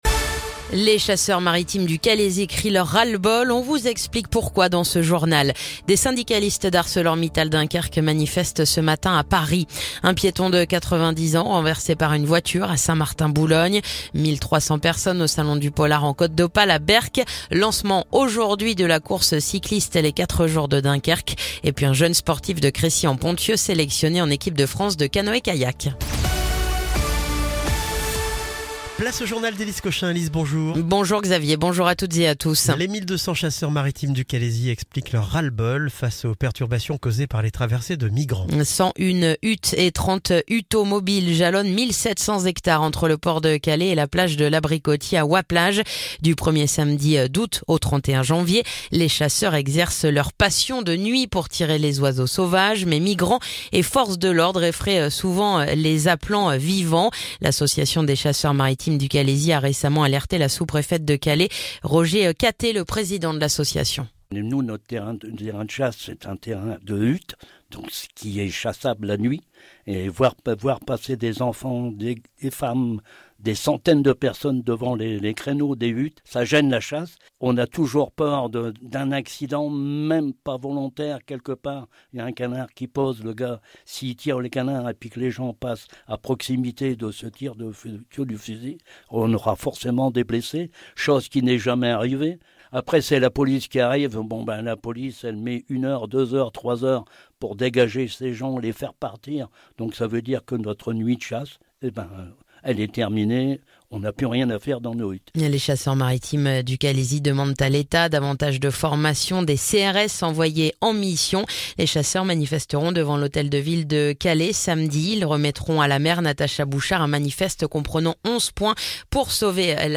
Le journal du mardi 13 mai